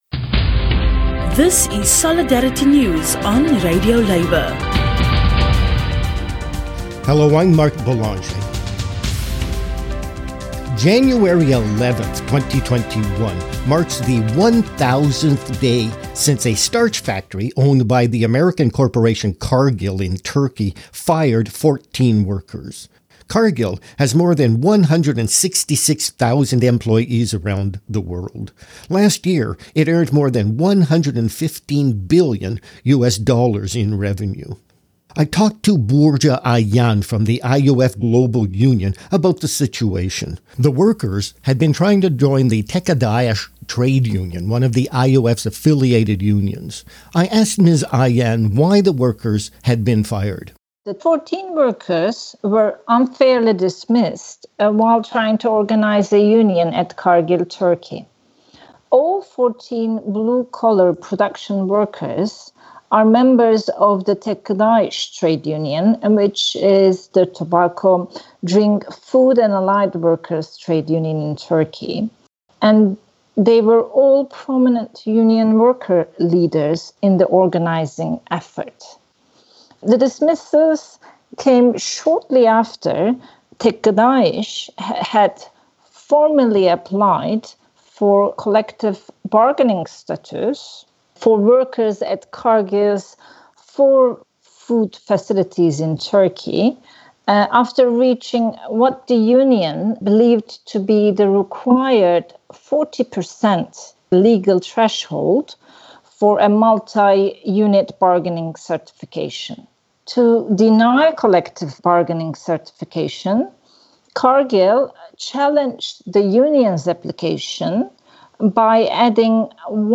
January 11, 2021 was the 1,000 day anniversary of the firing of 14 workers at the Cargill starch plant in Turkey. An interview